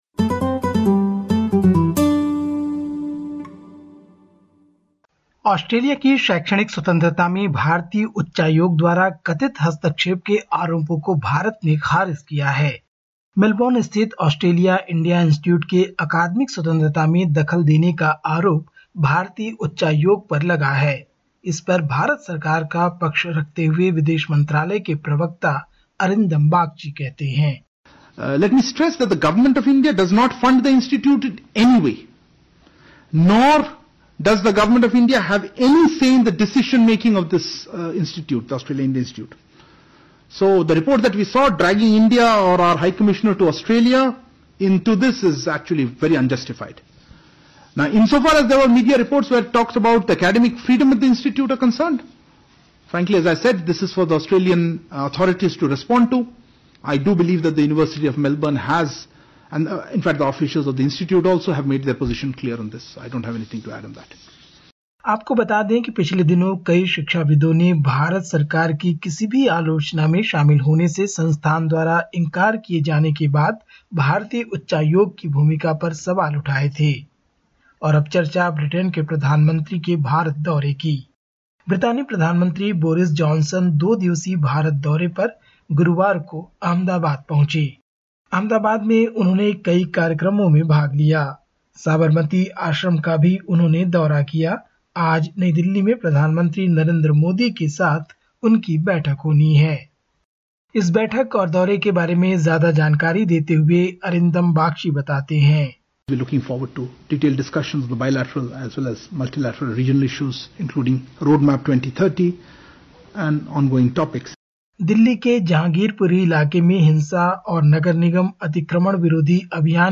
Listen to the latest SBS Hindi report from India. 22/04/2022